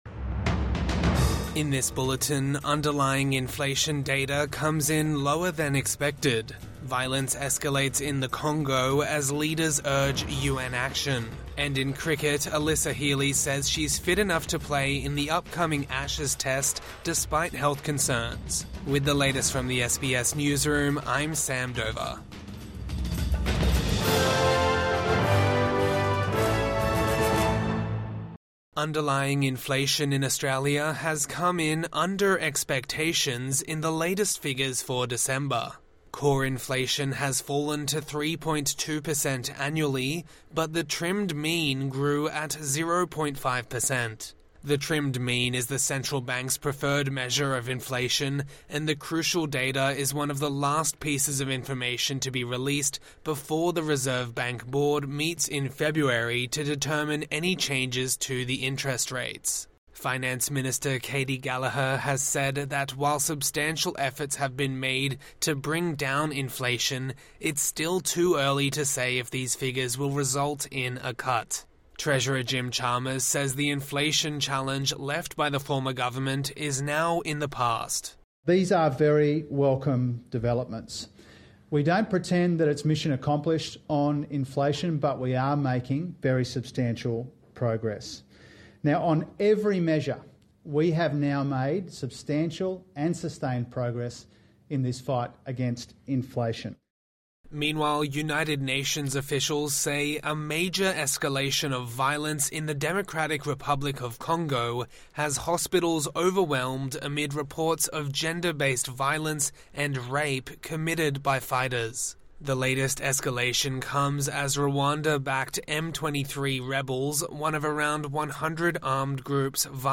Evening News Bulletin 29 January 2025